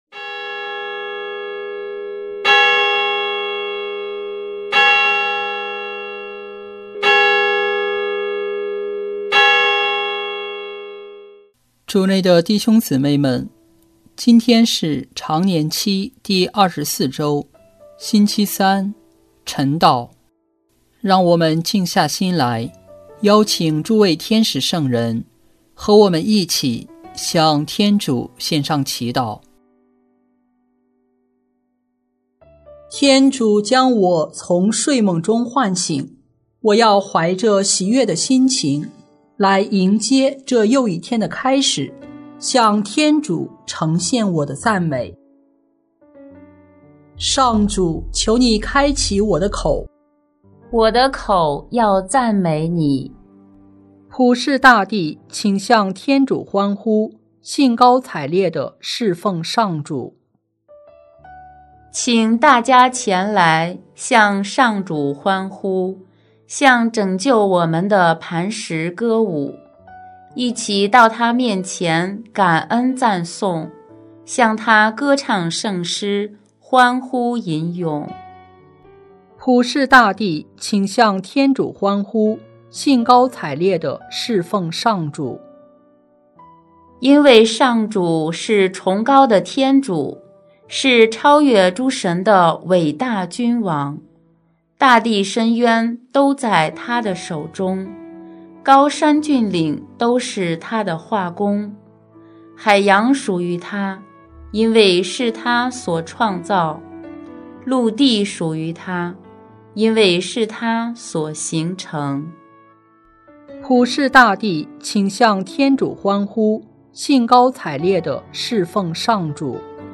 【每日礼赞】|9月17日常年期第二十四周星期三晨祷